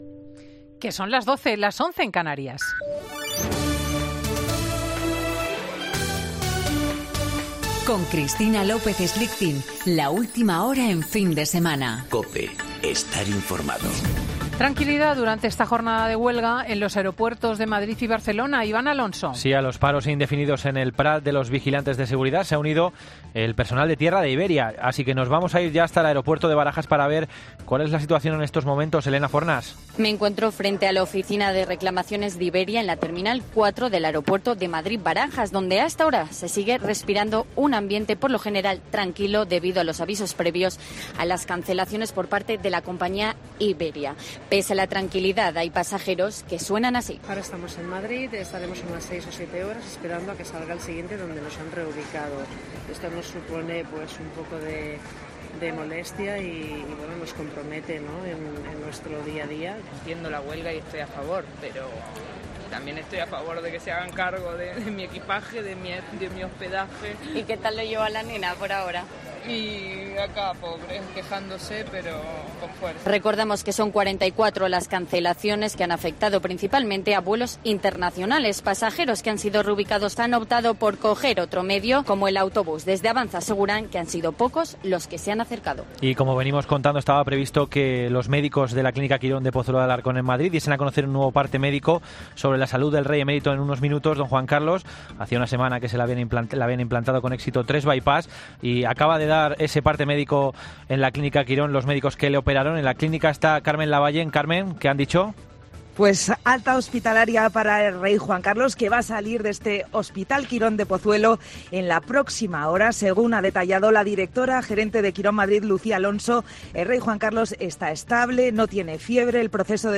Boletín de noticias de COPE del 31 de agosto de 2019 a las 12.00 horas